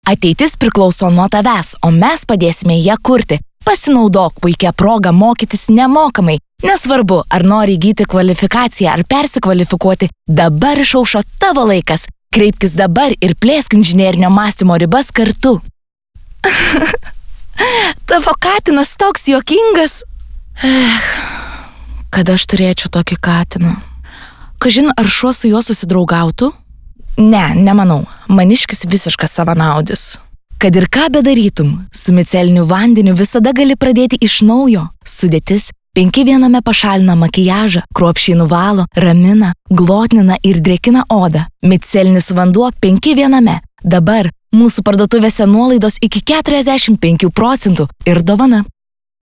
Diktoriai